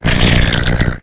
Cri_0837_EB.ogg